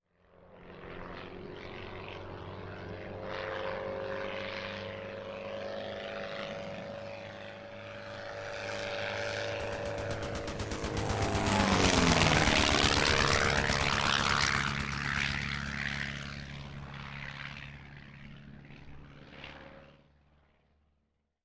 Caza de la Primera Guerra Mundial (Albatros)
Sonidos: Transportes